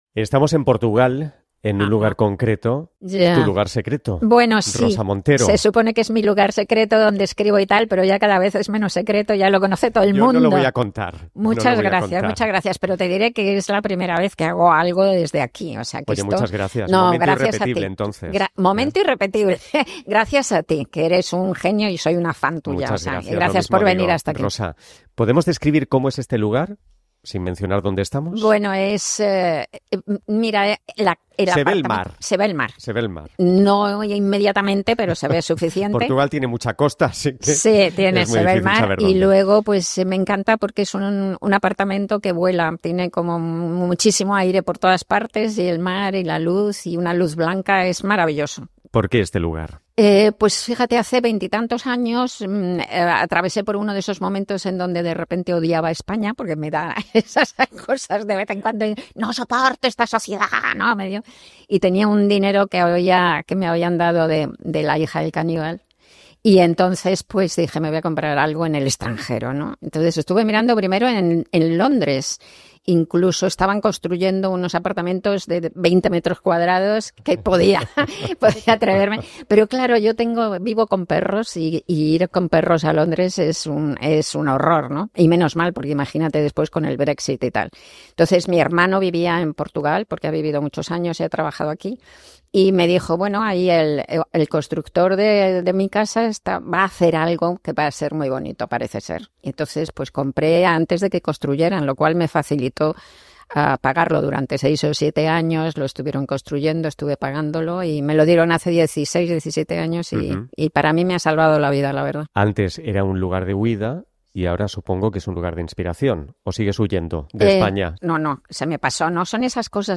7c8939d16fabcab192c8fc2ea3e74edc525c083f.mp3 Títol Radio 5 Emissora Radio 5 Barcelona Cadena RNE Titularitat Pública estatal Nom programa Irrepetibles Descripció Primera edició del programa a Radio 5. Entrevista a l'escriptora Rosa Montero feta al lloc on escriu a Portugal.